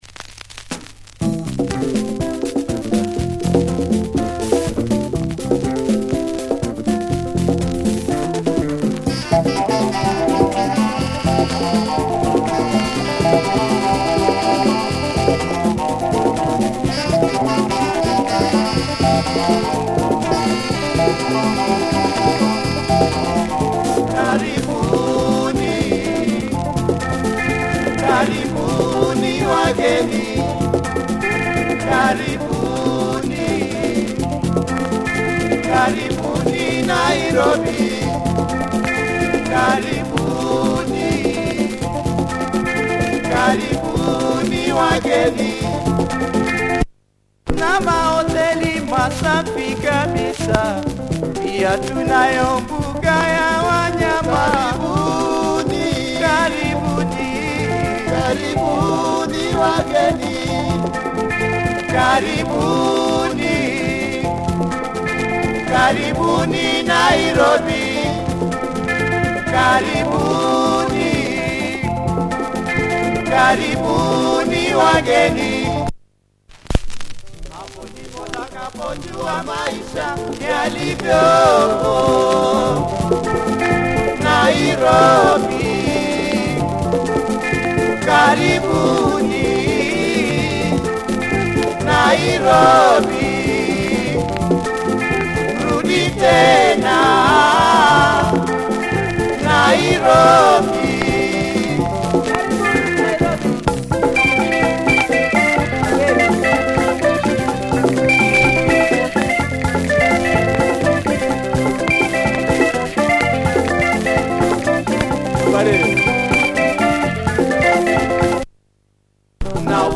Catchy pop effort